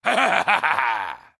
beyond/Assets/Sounds/Enemys/Male/laugch1.ogg at main
laugch1.ogg